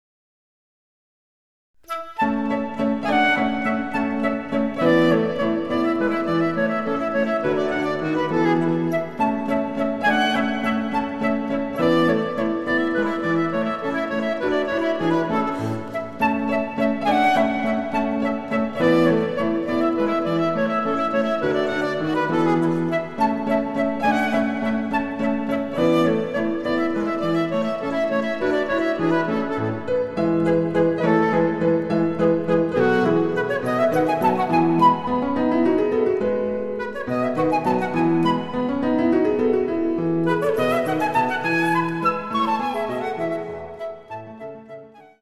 ★フルートの名曲をピアノ伴奏つきで演奏できる、「ピアノ伴奏ＣＤつき楽譜」です。
ソナタ　イ長調　K. V. １２